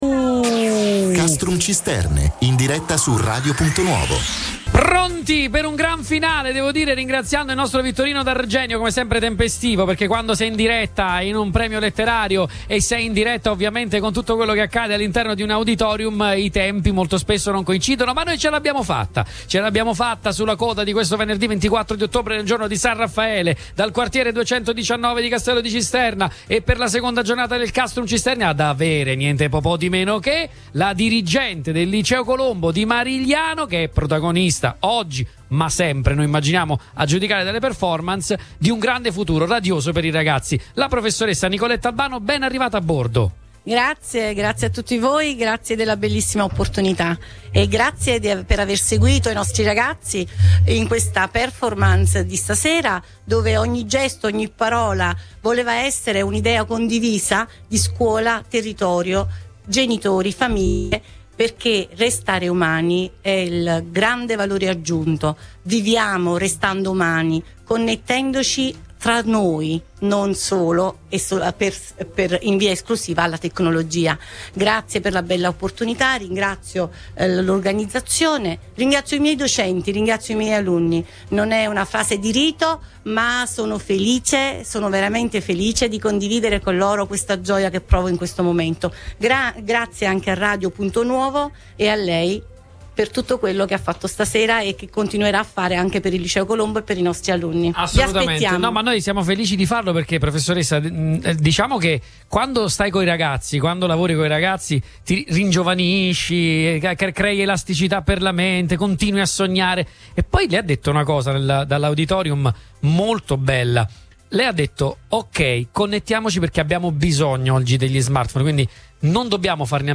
Castello di Cisterna (NA) –Ieri sera all’Auditorium Enrico De Nicola un messaggio potente, in occasione del Premio Letterario Castrum Cisternae: la necessità di bilanciare la tecnologia con l’umanità.